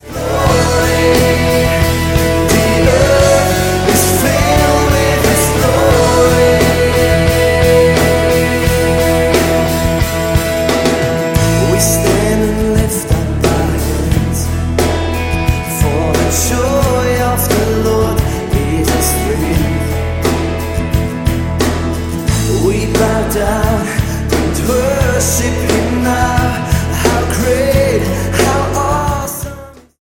Diese Worship-CD wurde am Crea Meeting live aufgenommen.